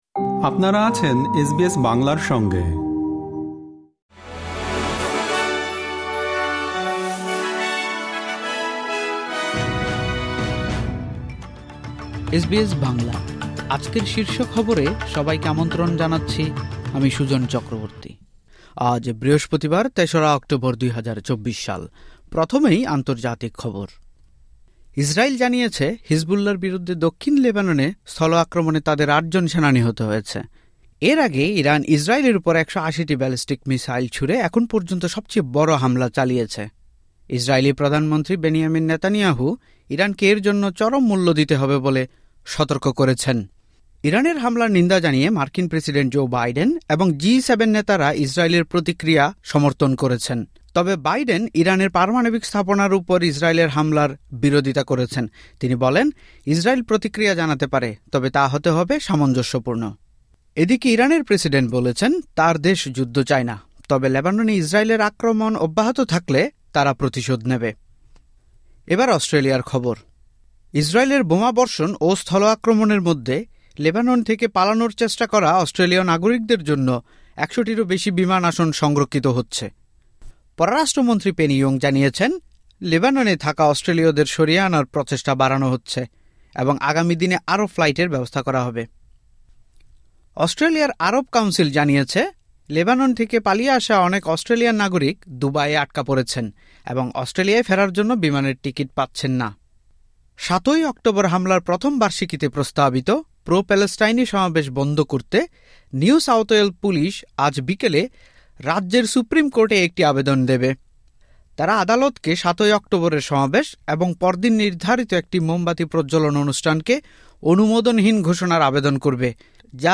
এসবিএস বাংলা শীর্ষ খবর: ৩রা অক্টোবর, ২০২৪